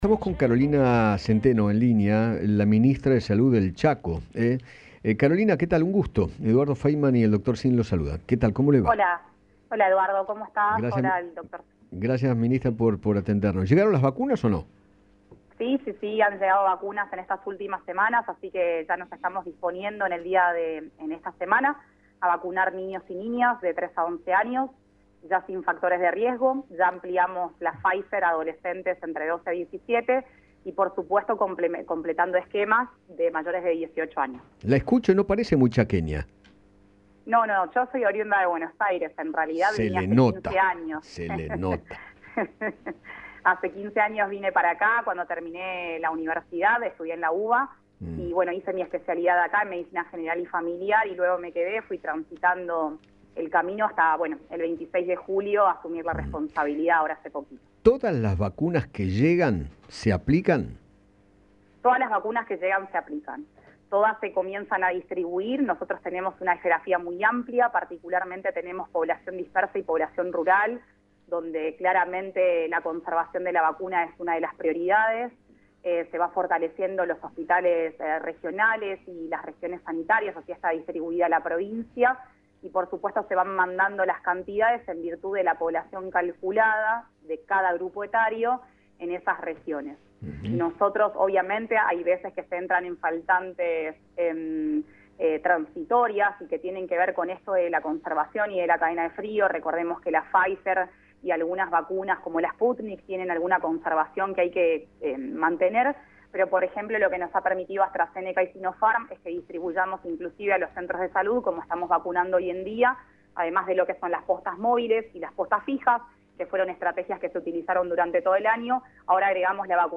Carolina Centeno, ministra de Salud de Chaco, dialogó con Eduardo Feinmann sobre el retraso en la carga de datos de vacunados y aseguró que tienen al 60% de la población objetivo vacunada, pese a que en el Monitor Nacional figura solo el 48%.